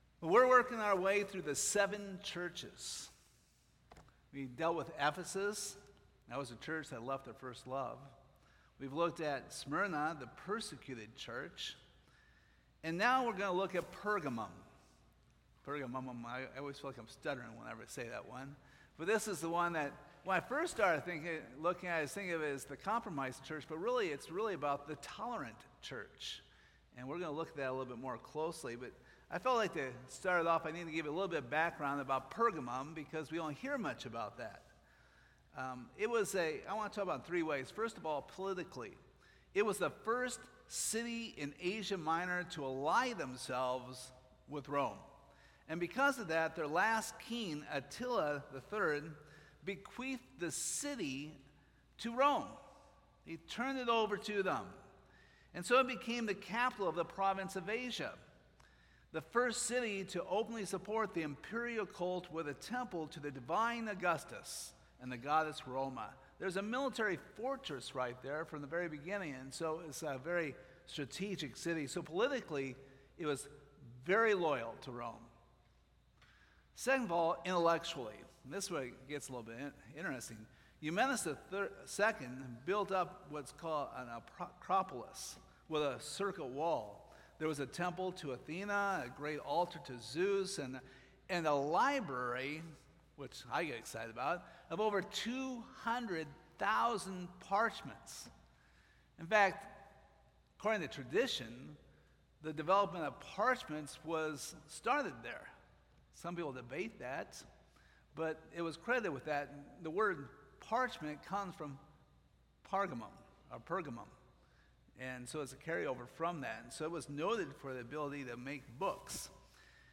Revelation 2:12-17 Service Type: Sunday Morning Now we are at the third church in this series of seven.